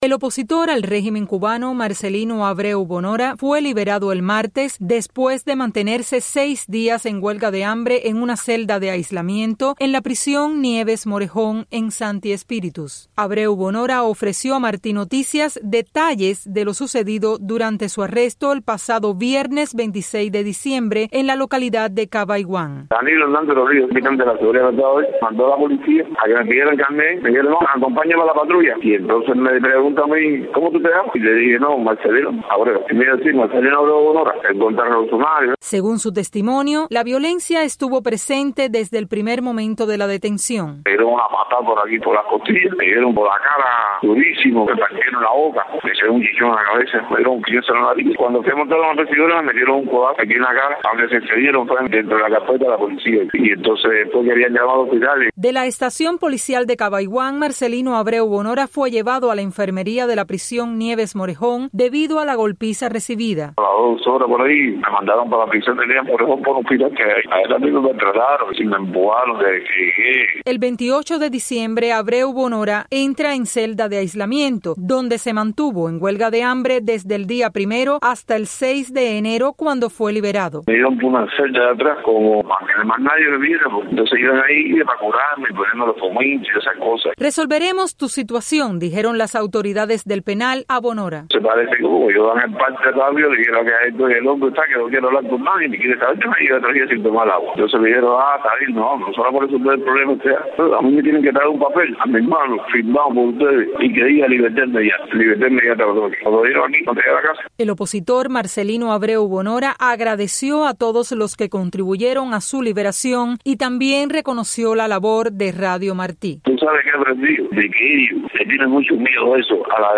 Noticias de Radio Martí